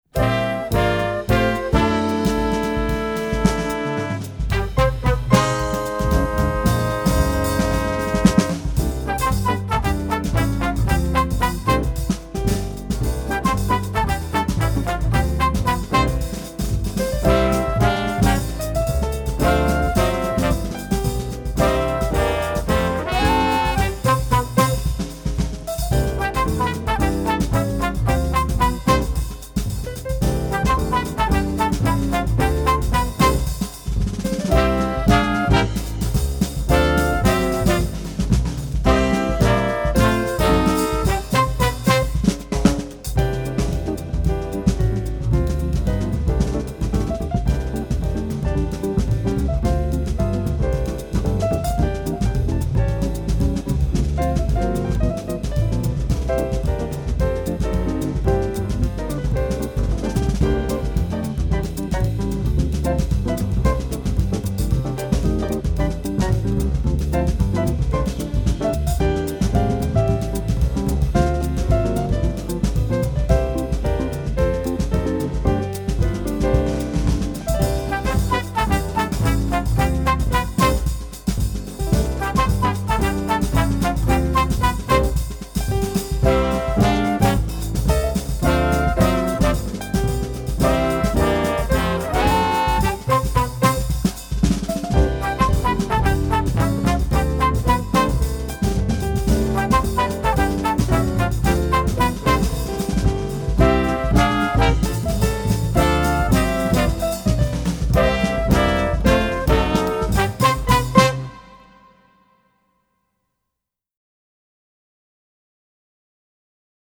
Gattung: Combo
Besetzung: Ensemble gemischt